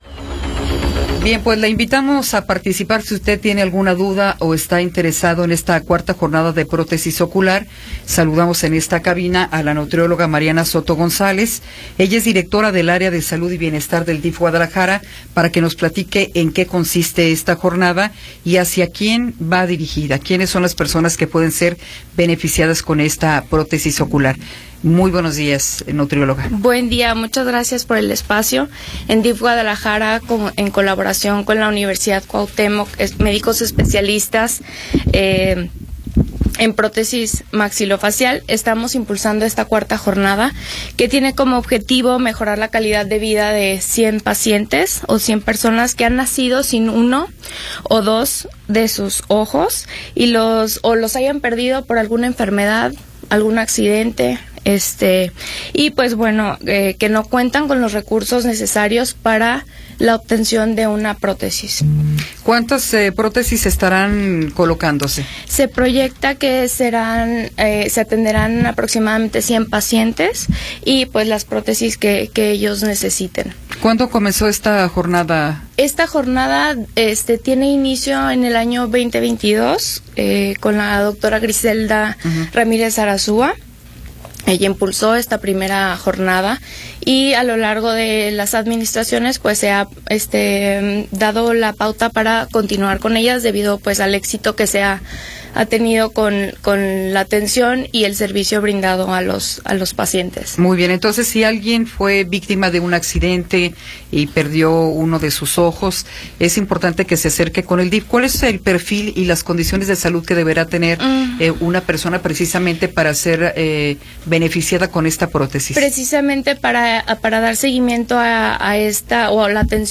Entrevistas
entrevista-2.m4a